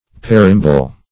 Search Result for " parembole" : The Collaborative International Dictionary of English v.0.48: Parembole \Pa*rem"bo*le\, n. [NL., from Gr.